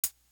Closed Hats
Worst To Worst Hat.wav